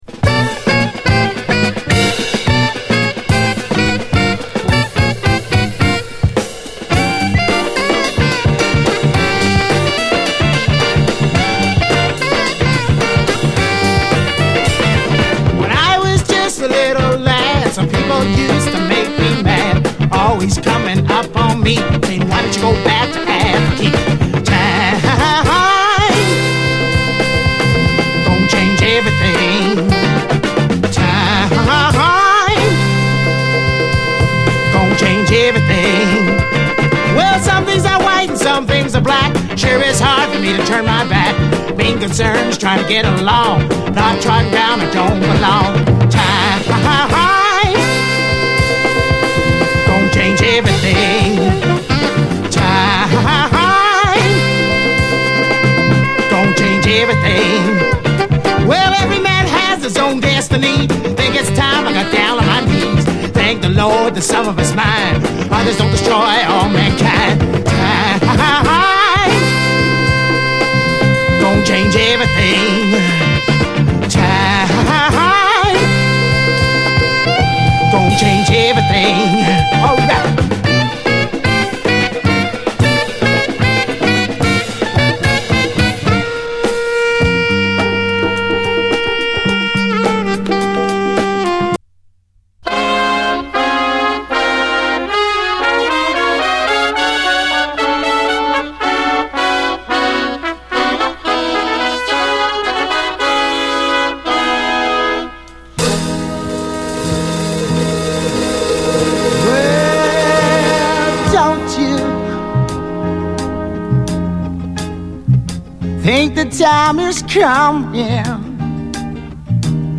SOUL, 70's～ SOUL
イントロからインパクト大、ガツンとアーシー＆ファンキーなレアグルーヴ
哀愁系メロウ・ソウル